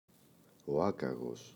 άκαγος [Ꞌakaγos]